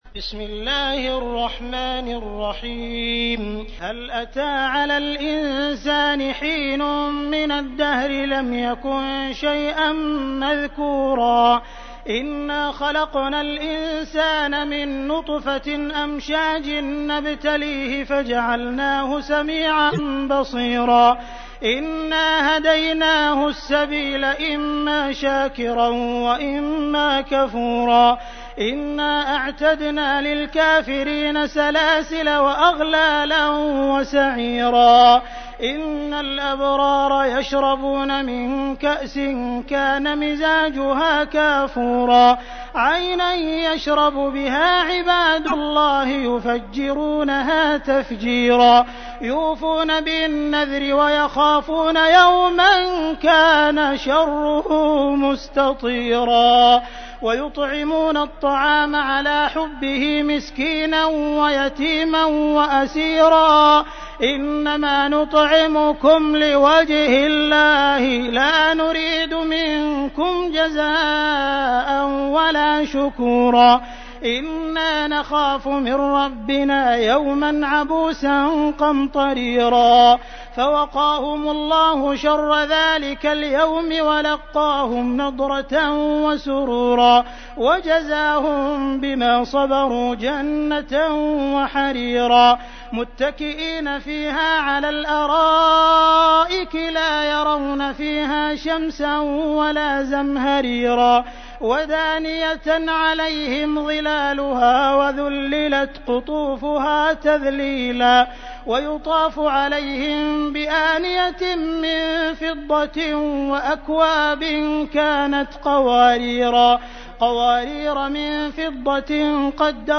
تحميل : 76. سورة الإنسان / القارئ عبد الرحمن السديس / القرآن الكريم / موقع يا حسين